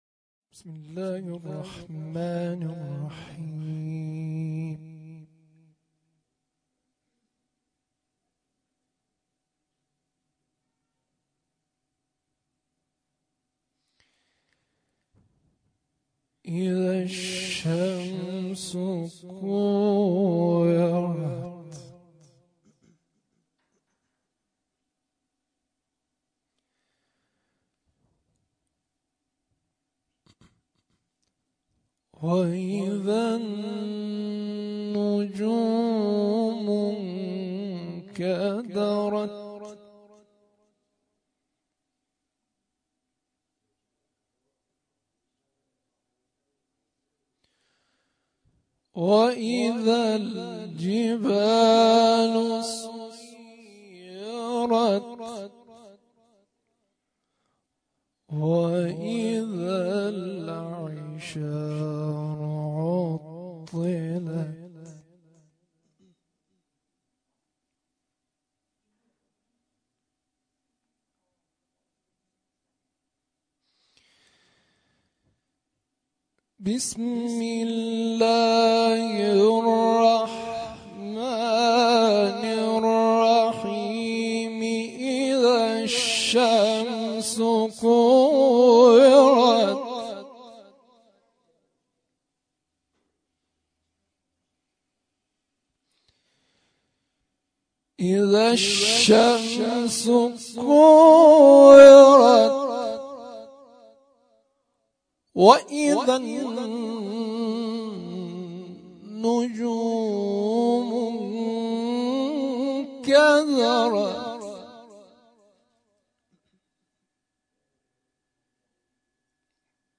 تلاوت قرآن کریم
شب سوم ویژه برنامه فاطمیه دوم ۱۴۳۹